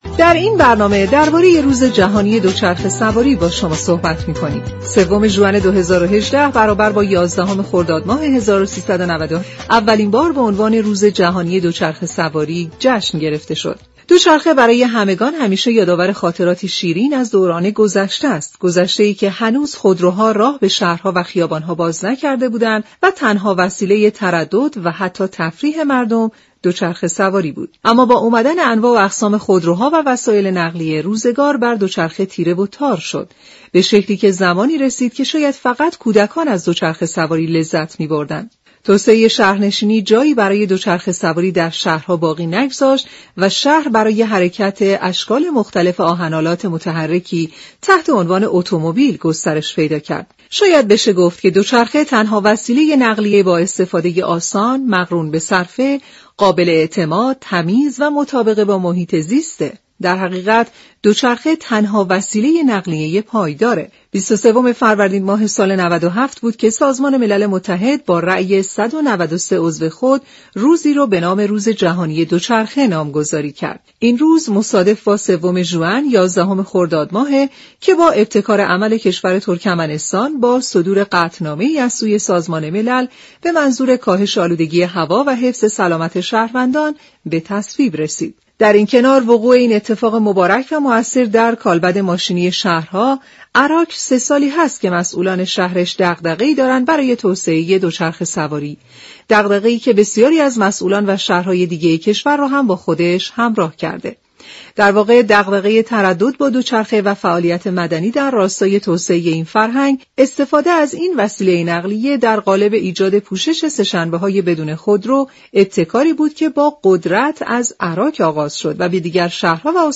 برنامه سیاره آبی شنبه تا چهارشنبه هر هفته ساعت 15:30 از رادیو ایران پخش می شود این گفت و گو را در ادامه می شنوید.